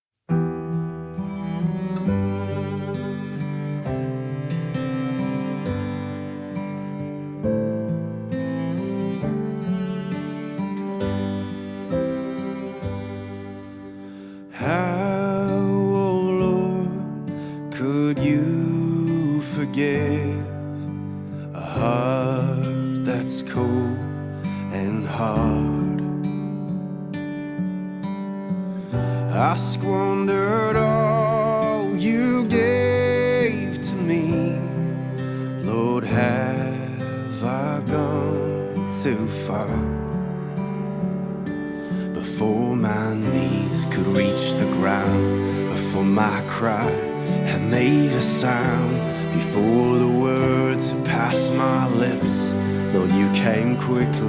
Christian Music